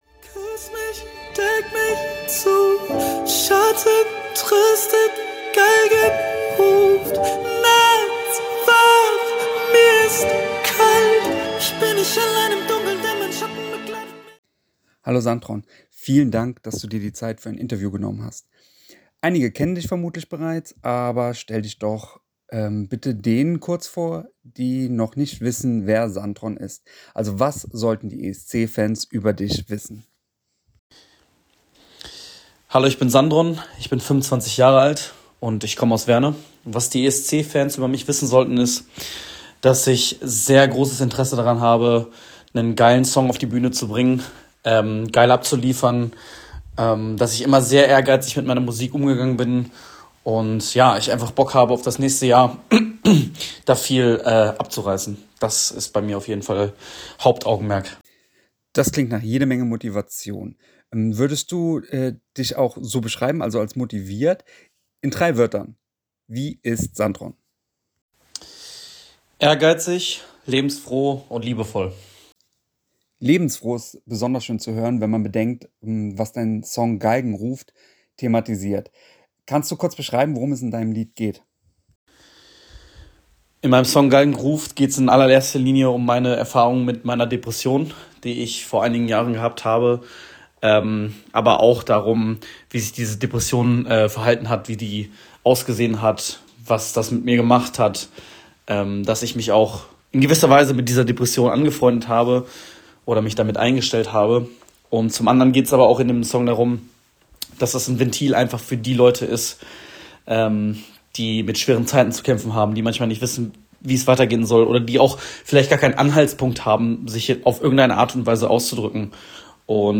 Hörfassung des Interviews: